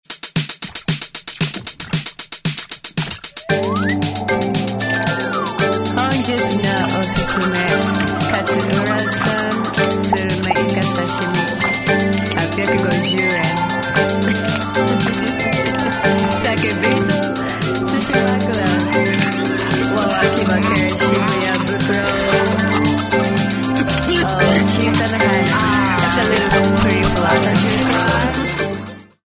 Hip  Hop
rapping